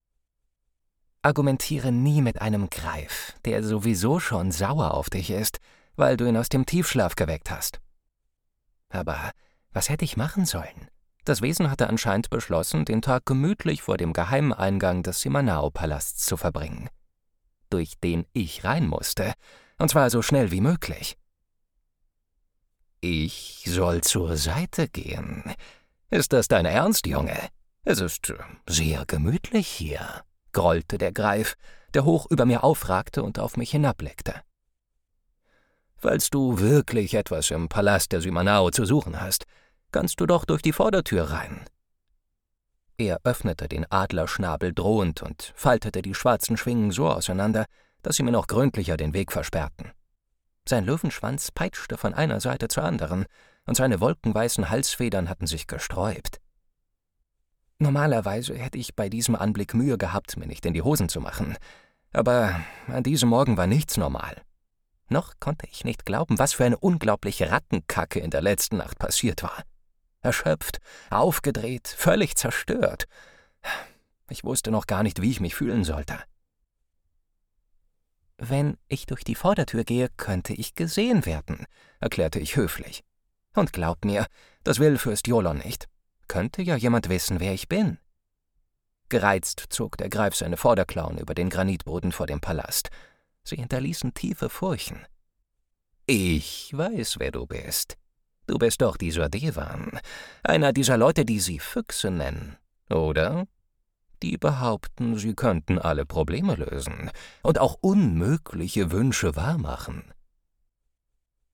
Stimmfarbe: authentisch, klar, warm